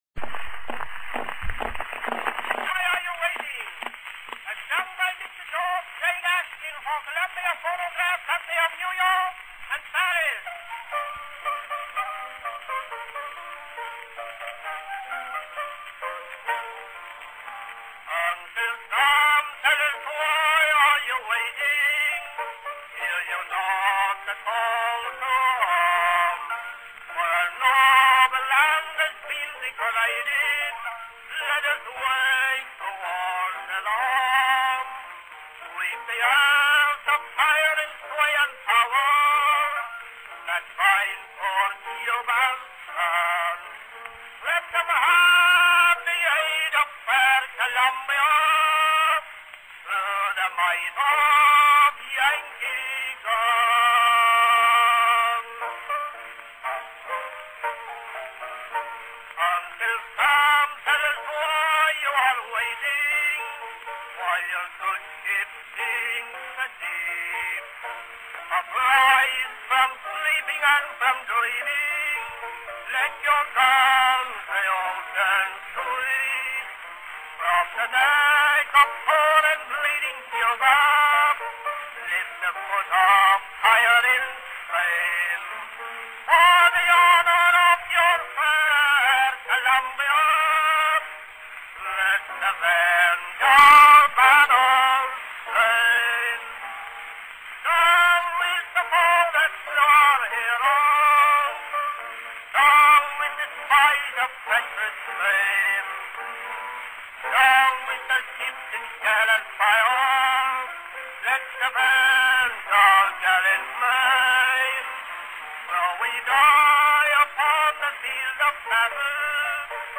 Music (performing arts genre)
Recorded in 1898.